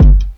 Dark Shady Kick.wav